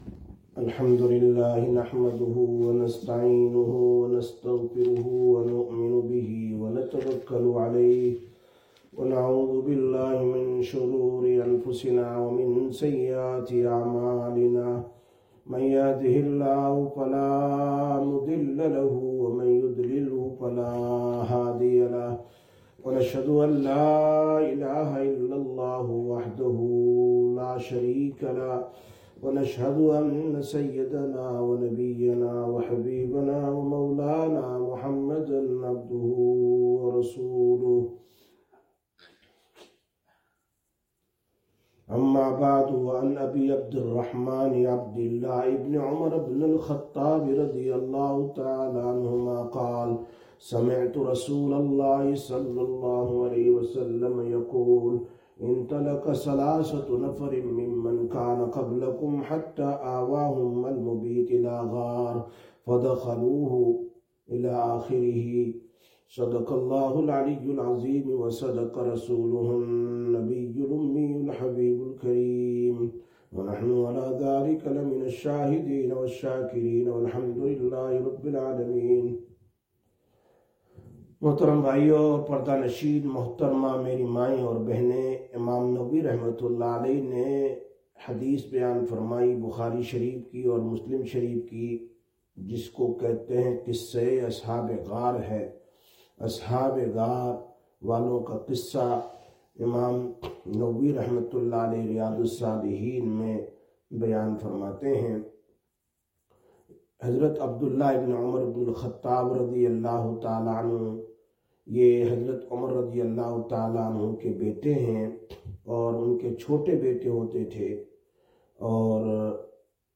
19/02/2025 Sisters Bayan, Masjid Quba